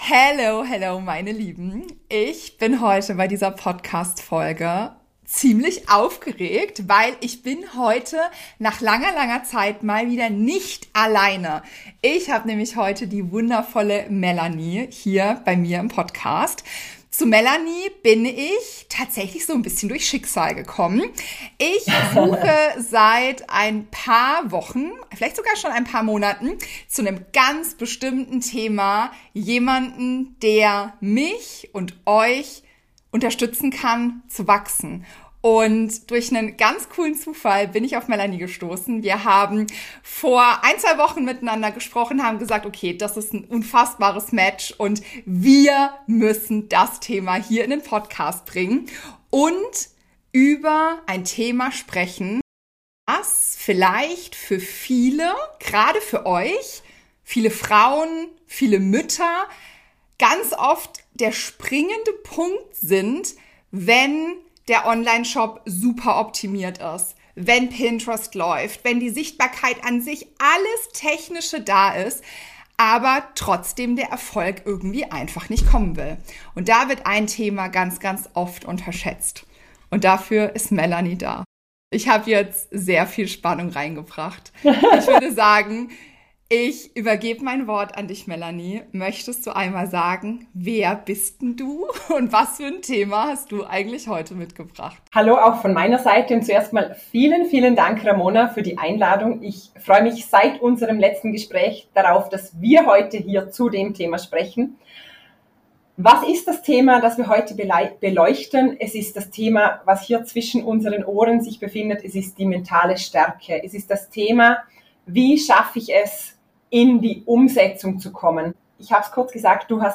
Mentales Business: Warum Erfolg im Kopf beginnt — ein Interview